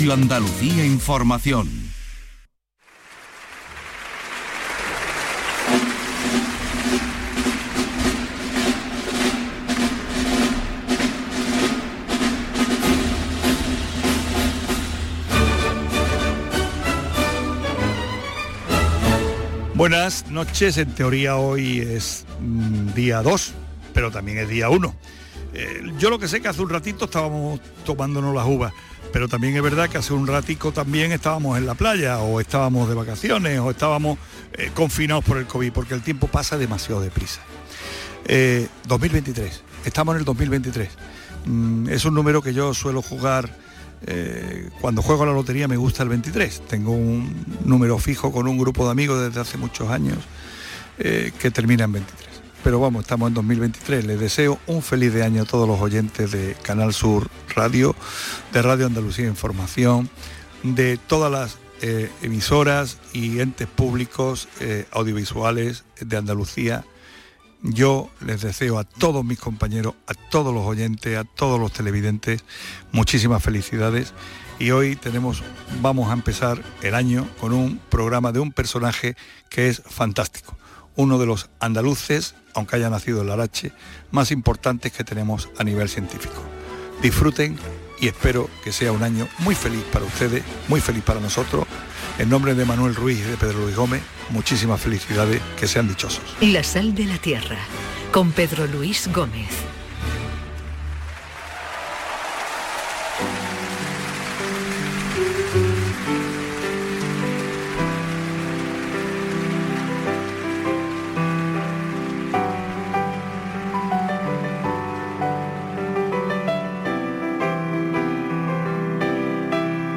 El catedrático de la Universidad de Córdoba, considerado como uno de los más grandes científicos españoles, Elías Fereres, especialista en agricultura y en temas relacionados con el agua y la sequía, será el primer invitado de ‘La sal de la Tierra. Conversaciones desde Andalucía’ de 2023 en Radio Andalucia Información, la noche del domingo al lunes (00:00 horas).